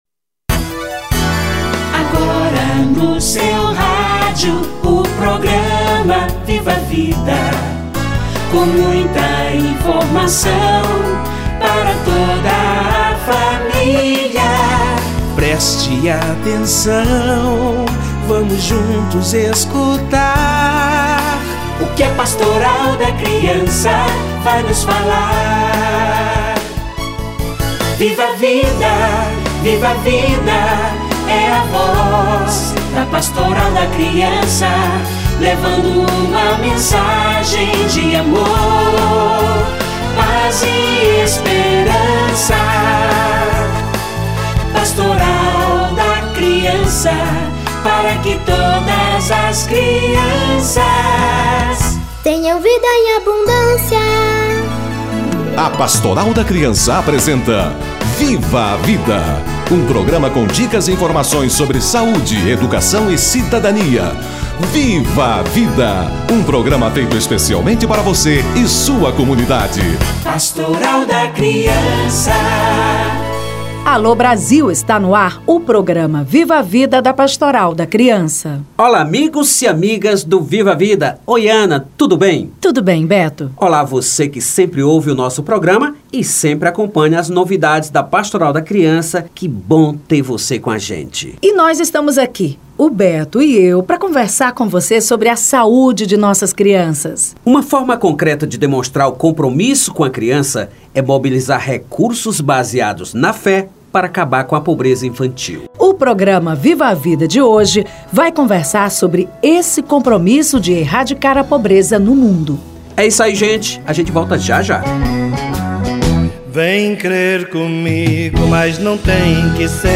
Criança e combate à pobreza - Entrevista